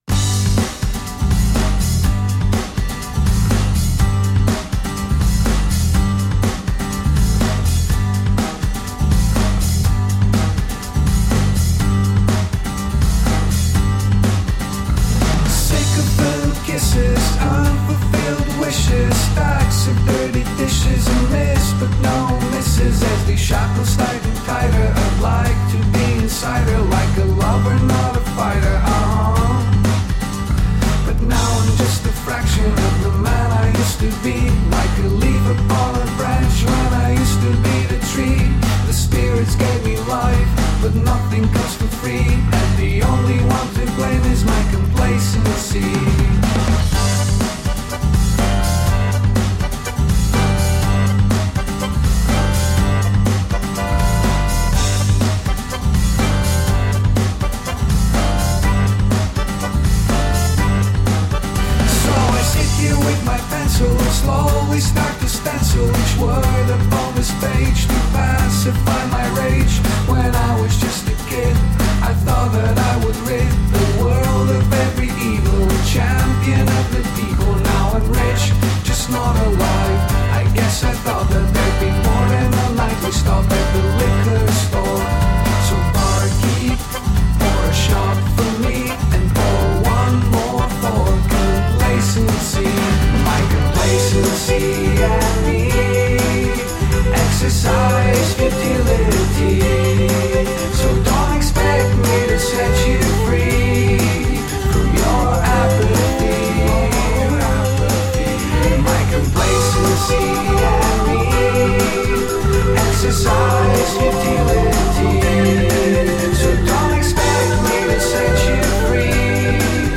The album bounces between rock and pop.
Tagged as: Electro Rock, Rock, Pop, Electro Pop